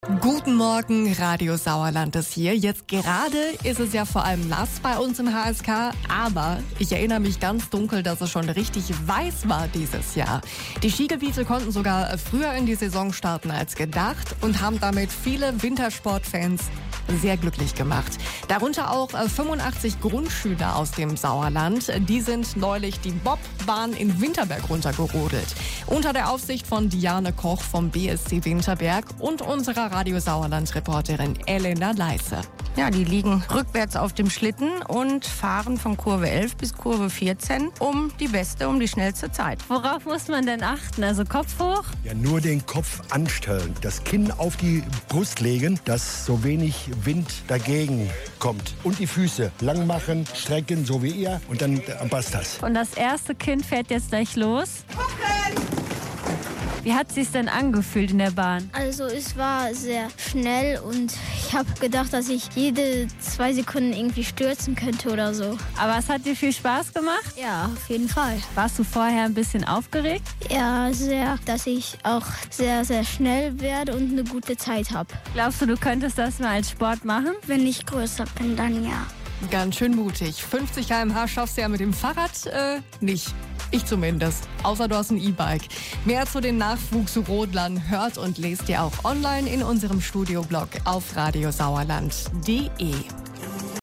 mitschnitt-repo-bobfahren.mp3